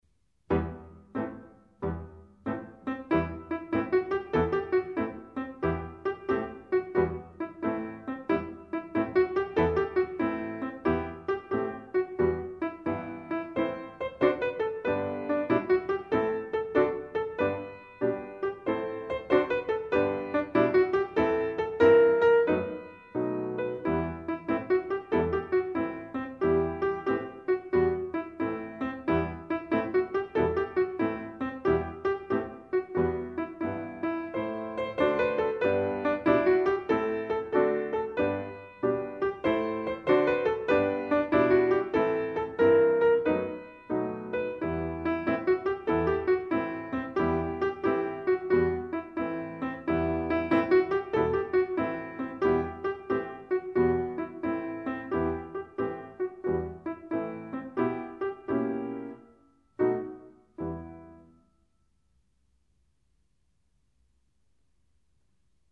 Enregistrement piano
5e-symph1mvtpiano-seul.mp3